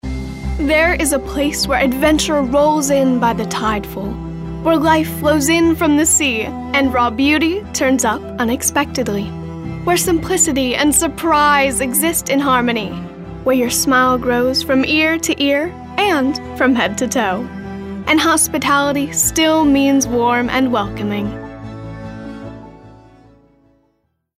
A bubbly warm voice to put your spots over the top!
Roll with the Tide anti-announcer, classy, compelling, friendly, promo, warm